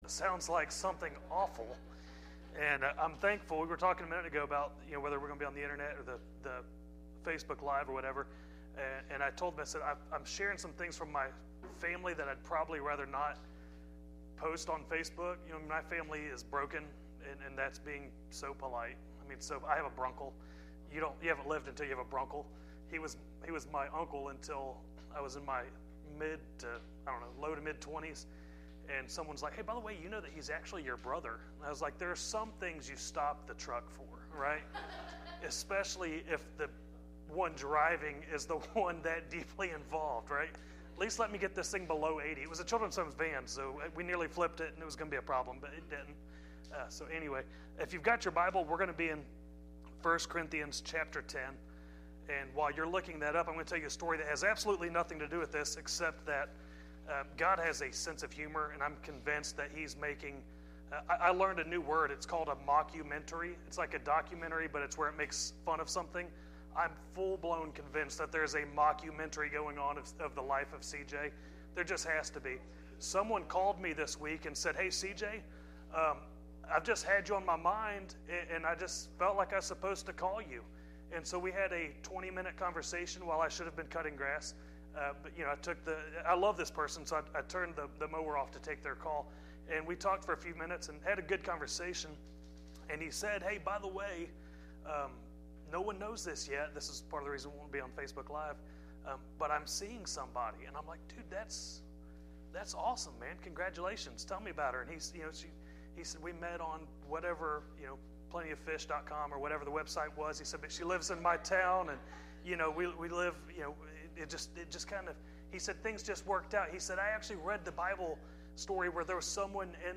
1 Corinthians 10:1-10 Service Type: Midweek Meeting « A Great Adventure With God